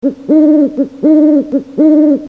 owl.mp3